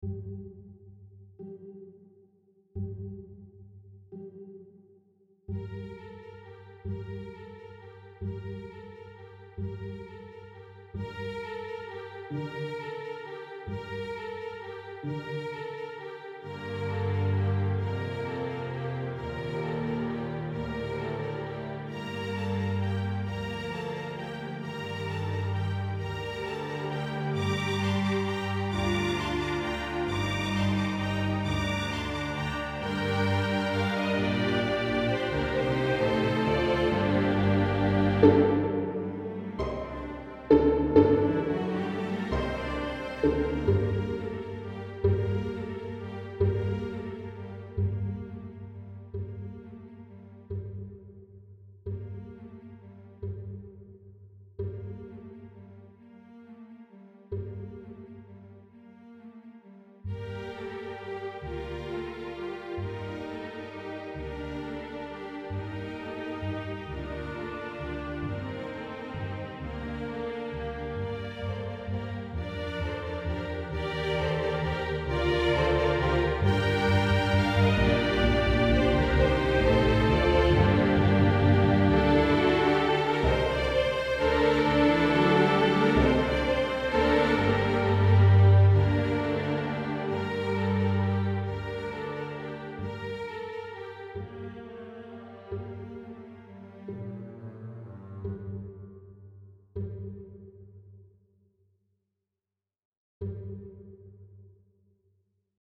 INTERMEDIATE, STRING QUARTET
Notes: harmonic pizz,
Key: G minor